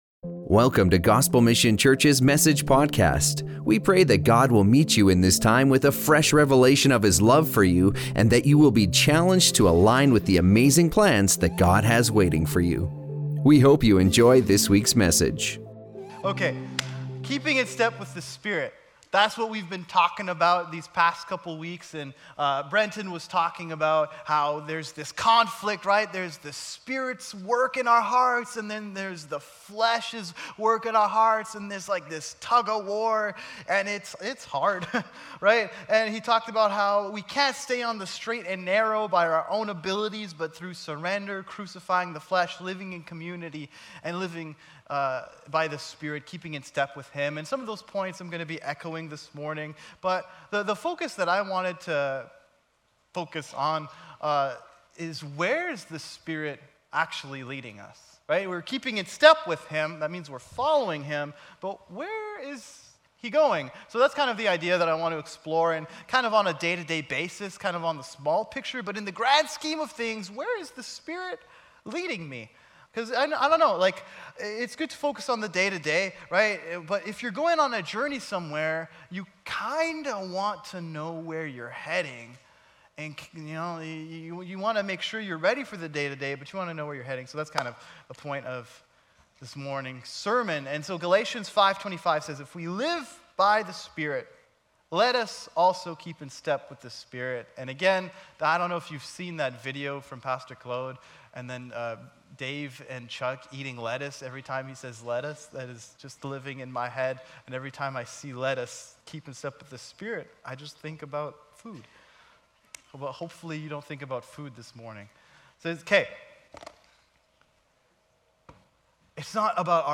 Explore how the Spirit leads us beyond mere actions into true transformation, shaping us into the likeness of Christ. This sermon invites you to reflect on surrendering every part of your life to become a dwelling place for God's Spirit, emphasizing faith expressed through love and continual obedience. Unveil the journey from external religious practices to a deep, Spirit-led renewal of the heart.